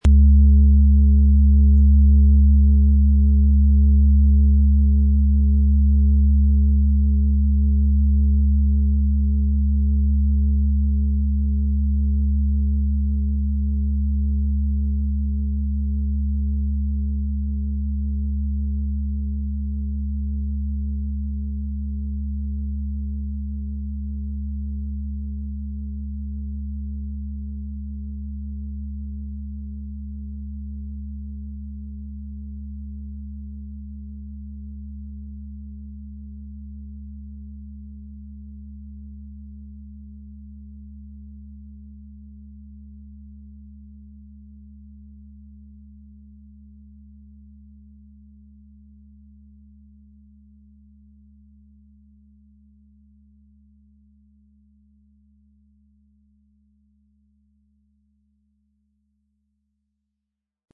Der Uranus als mittlerer Ton öffnet Räume für frische Impulse und neue Sichtweisen, während Merkur dich im Alltag beweglich und präsent hält.
MaterialBronze